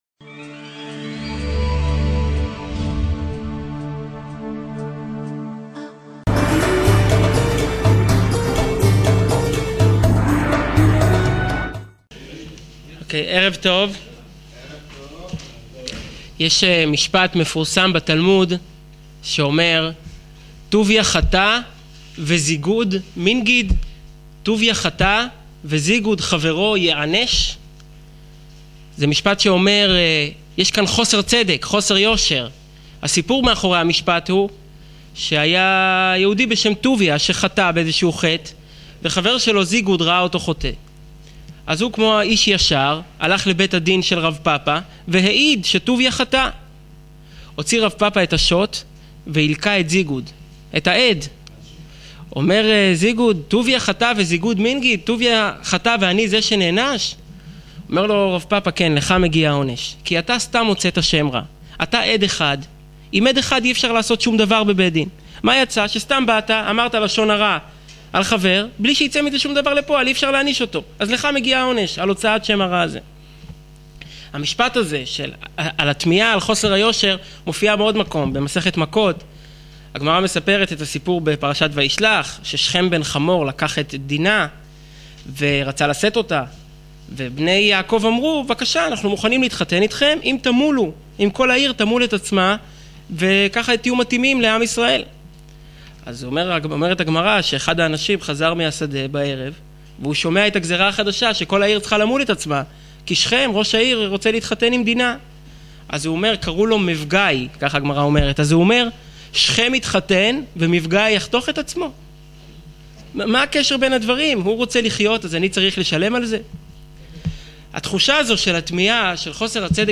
מה הבהמה אשמה שאתה חטאת? הקרבנות כמשל ● שיעור וידאו